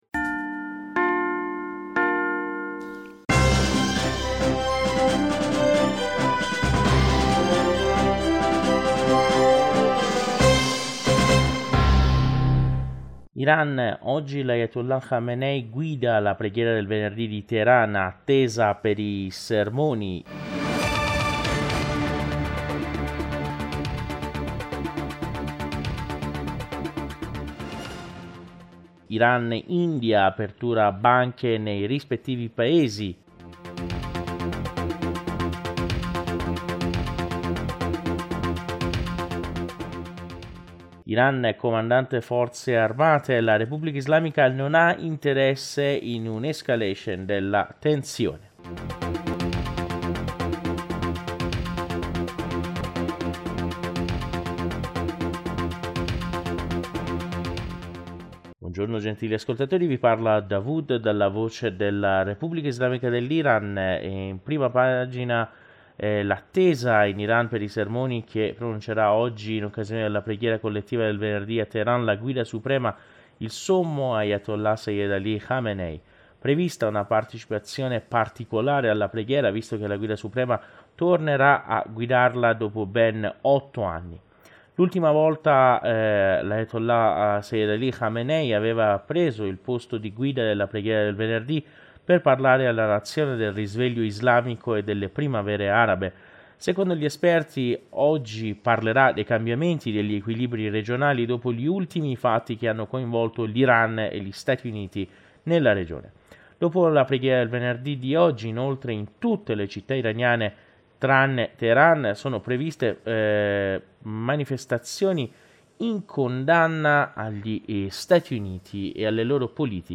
Giornale radio 2020-01-17 (mattino)